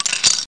1 channel
shatter.mp3